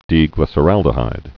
(dēglĭsə-răldə-hīd)